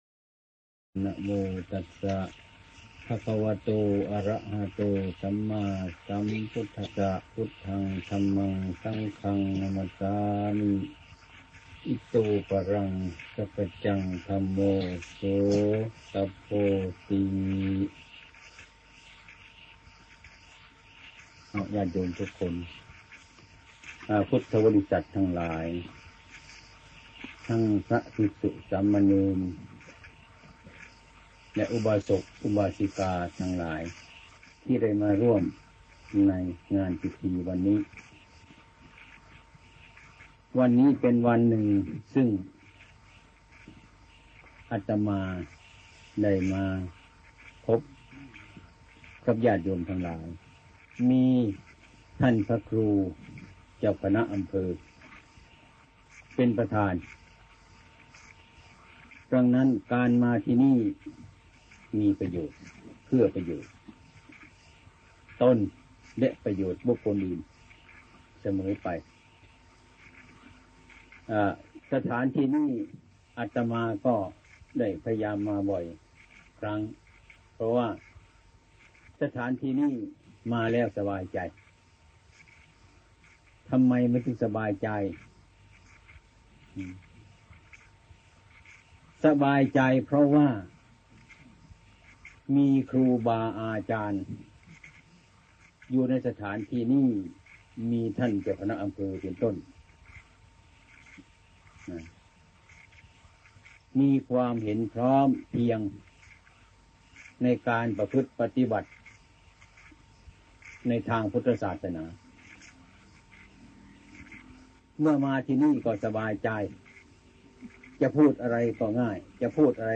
ดร อาจอง ชุมสาย ณ อยุธยา บรรยายเรื่อง อานุภาพพลังจิต